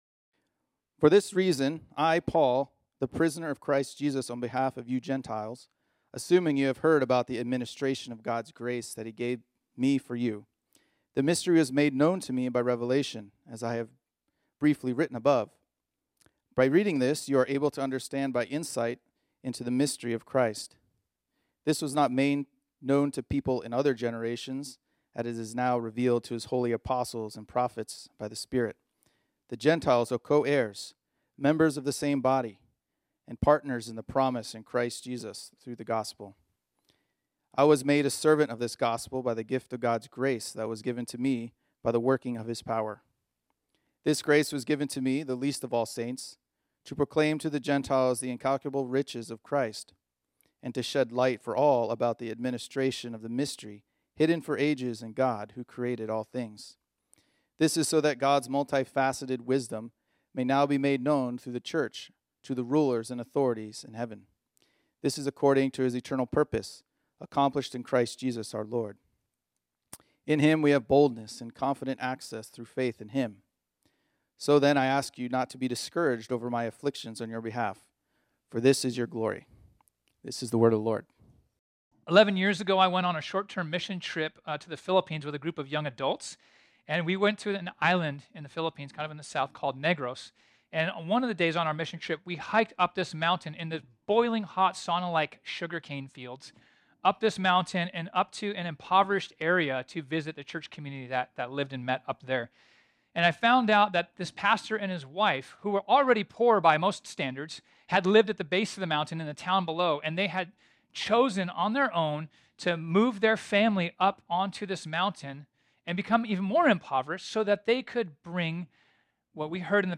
This sermon was originally preached on Sunday, October 8, 2023.